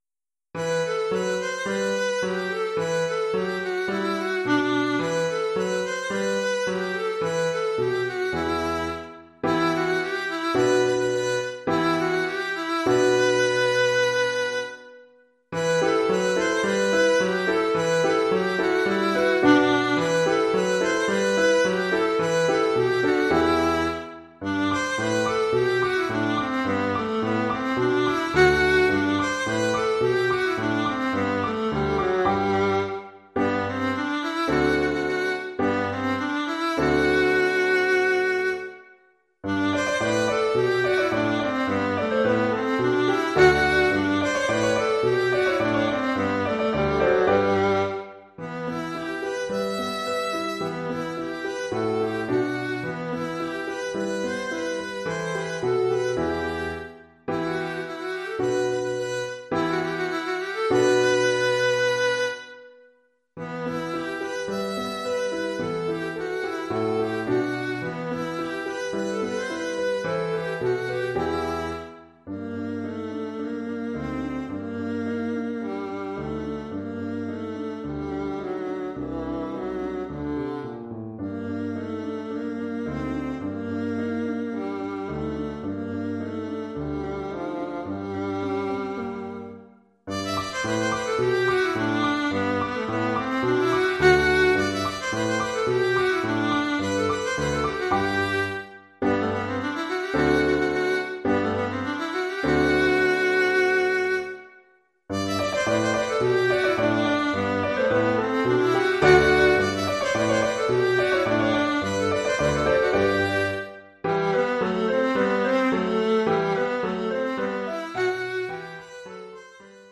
Formule instrumentale : Alto et piano
Oeuvre pour alto et piano.